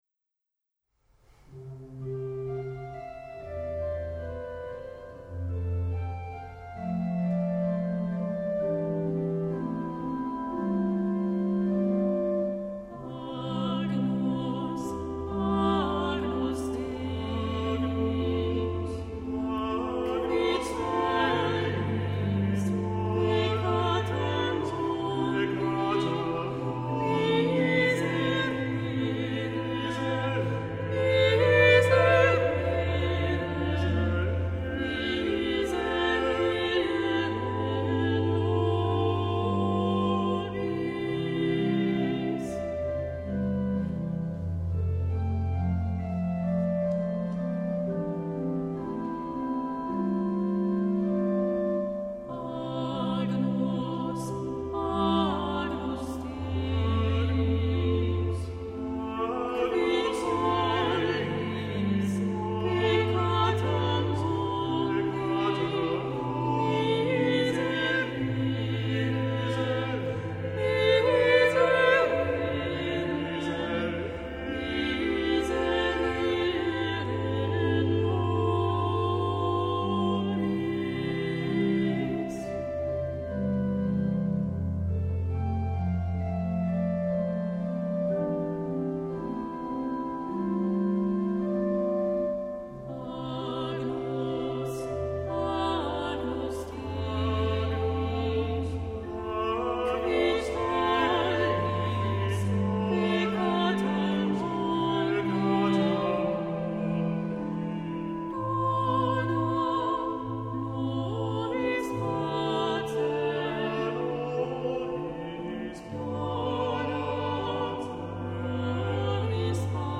SAB